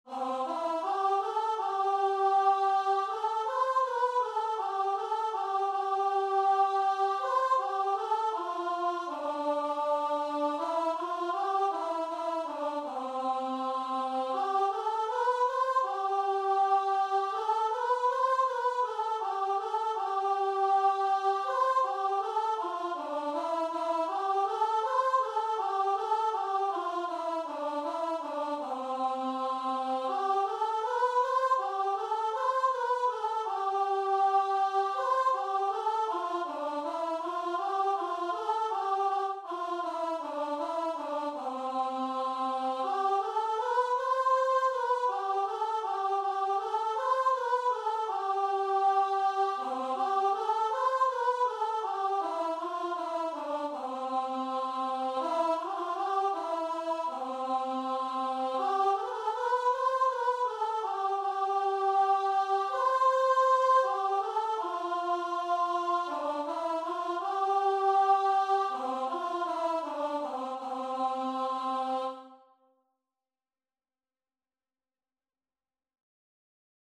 Traditional Music of unknown author.
4/4 (View more 4/4 Music)
C major (Sounding Pitch) (View more C major Music for Choir )
Choir  (View more Easy Choir Music)
Classical (View more Classical Choir Music)